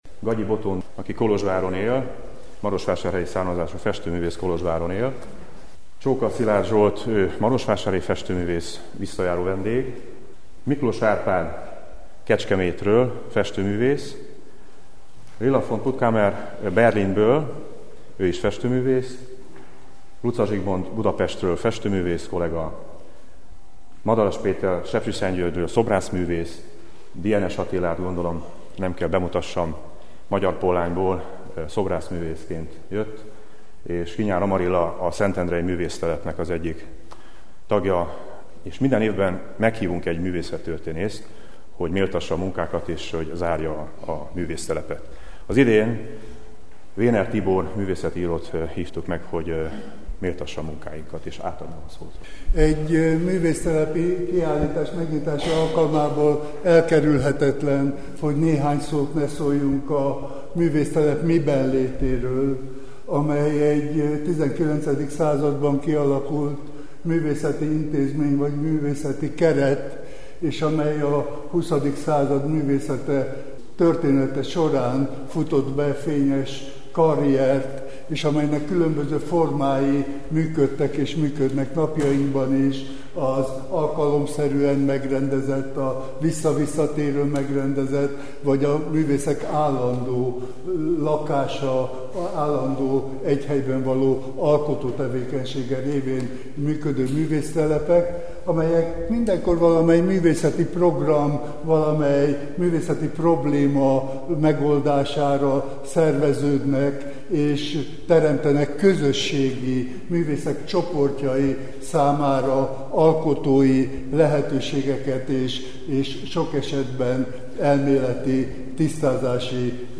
Kastelypark-Gernyeszeg-Megnyito.mp3